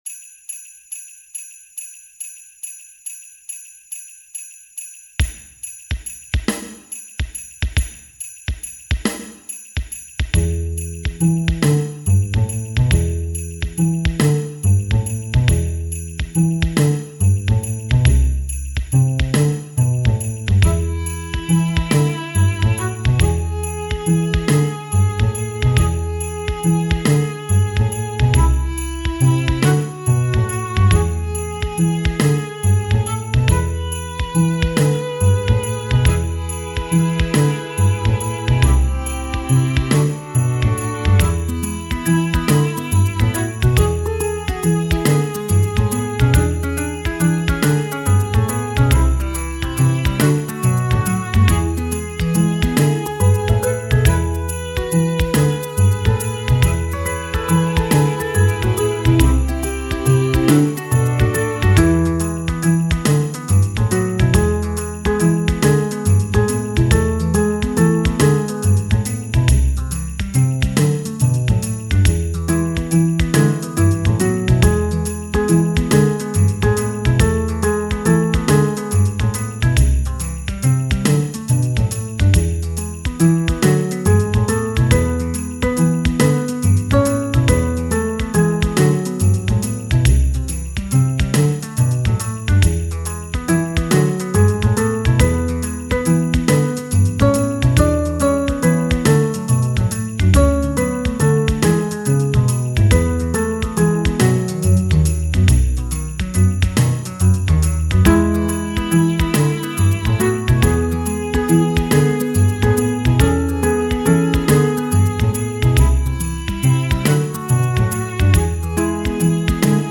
• 4 percussion samples
• 4 percussion patterns
• 4 SFZ sampled instruments